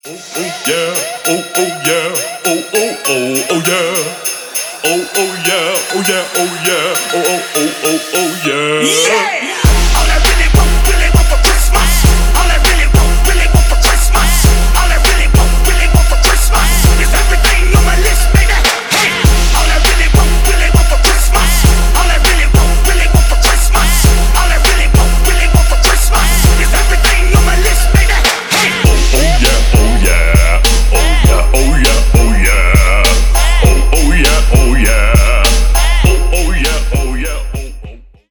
Рэп и Хип Хоп
весёлые